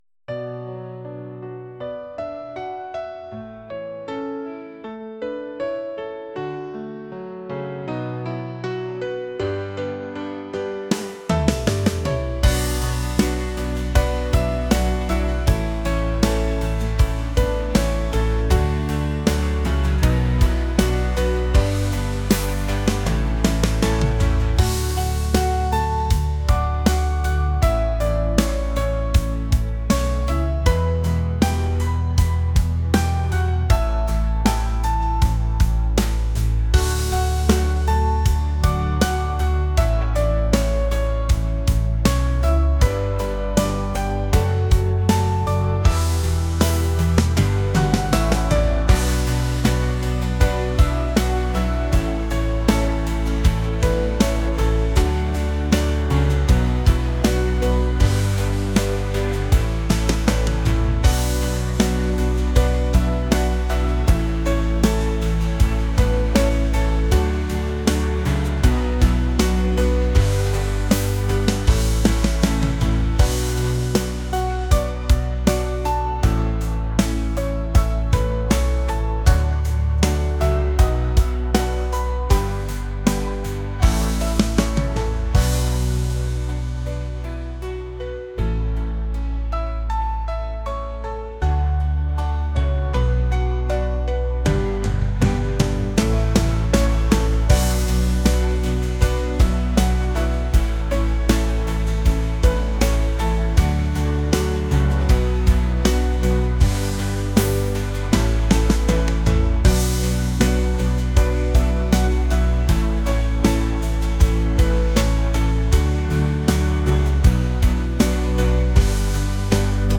pop | cinematic | soul & rnb